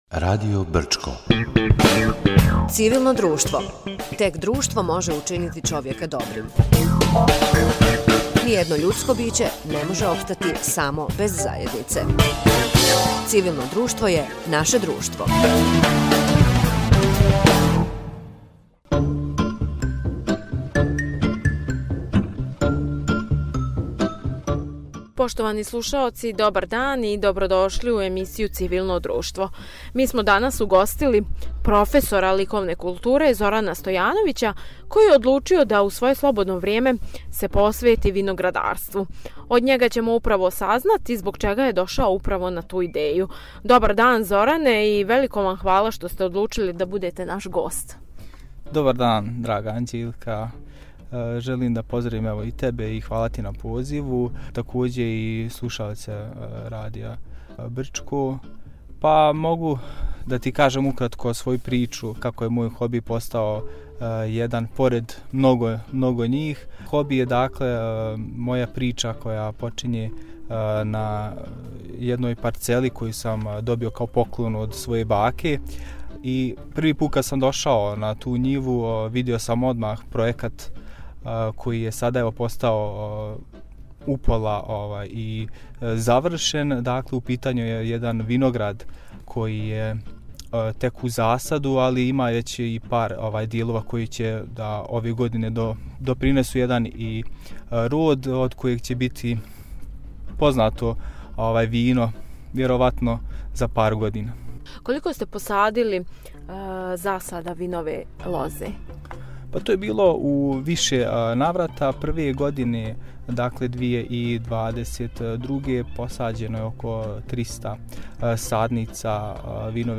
У емисији “Цивилно друштво” разговарали смо